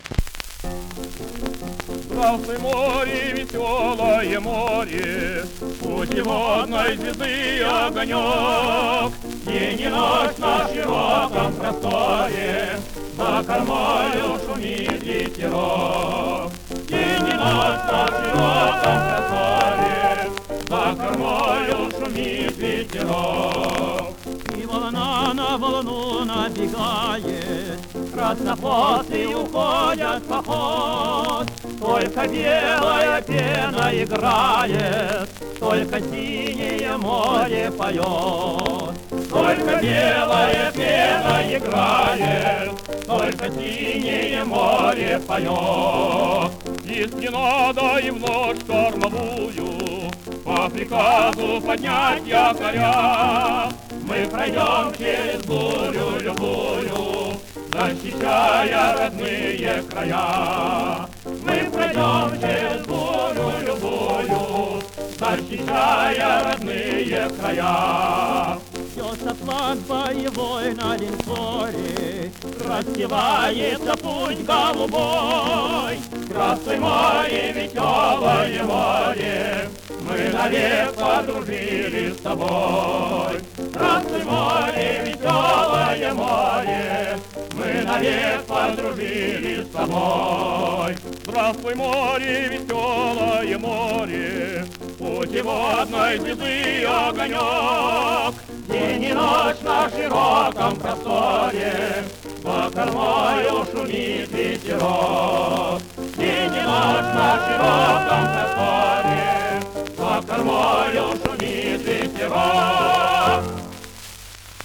Довольно типичная предвоенная краснофлотская песня.
фортепиано